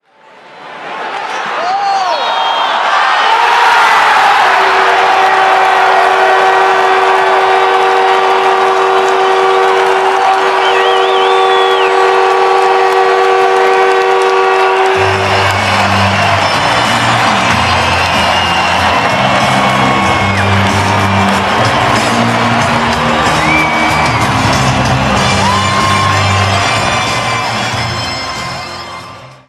hockey.wav